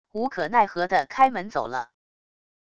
无可奈何的开门走了wav音频